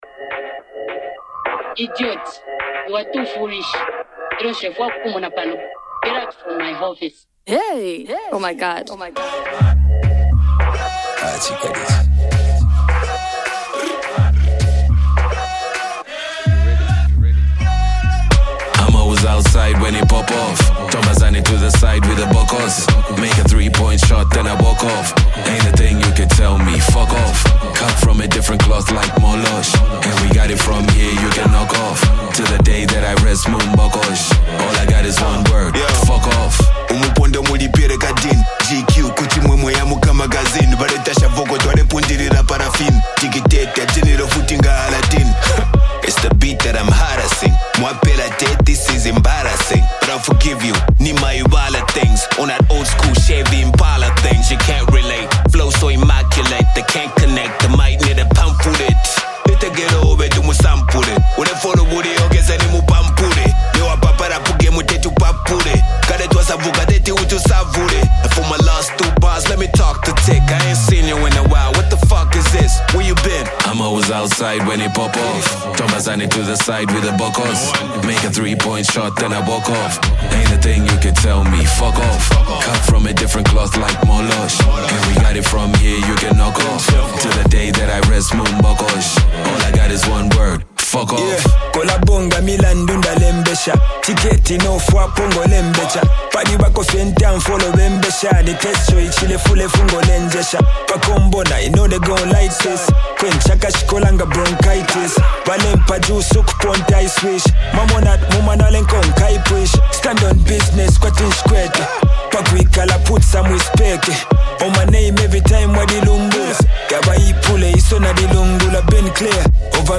Zambian Rap
Zambian hip-hop